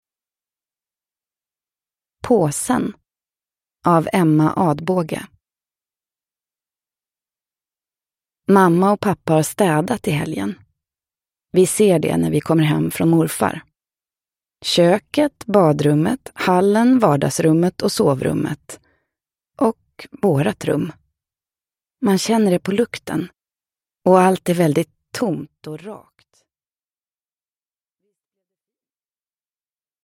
Påsen – Ljudbok